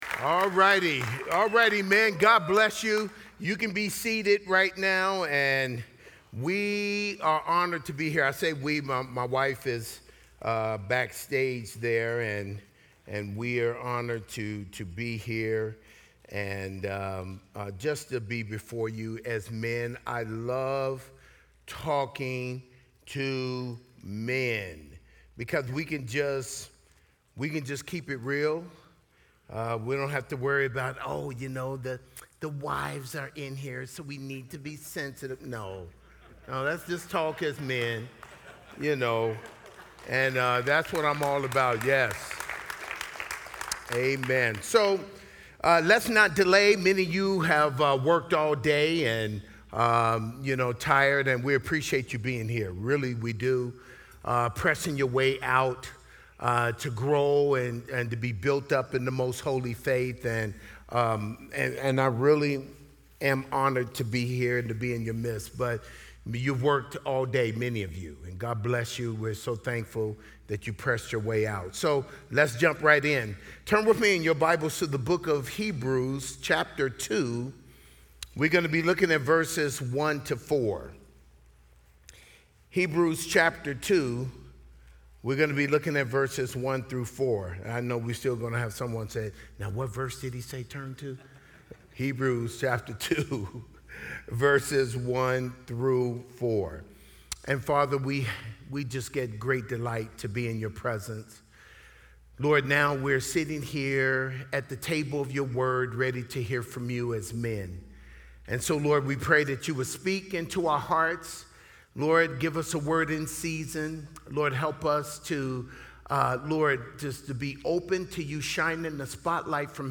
teach at the Men's Conference in Tucson, Arizona in 2025.